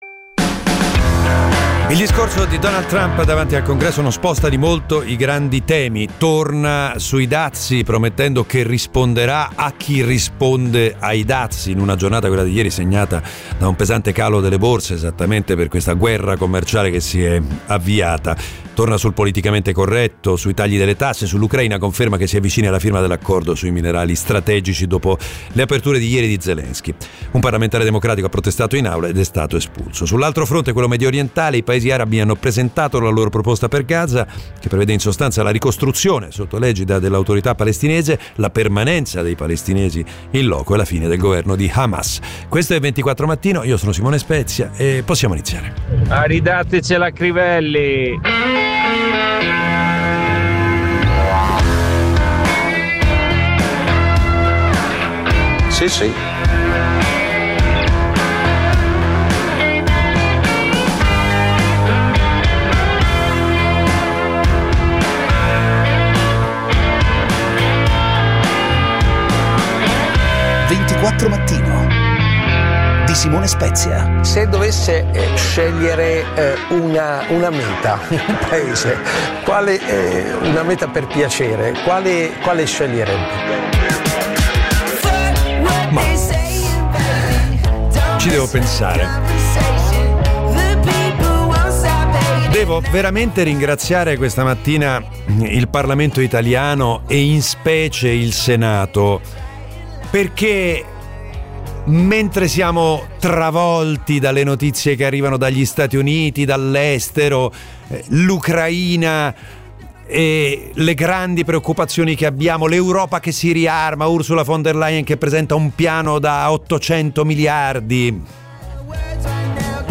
L'apertura di giornata con notizie e voci dei protagonisti, tutto in meno di 30 minuti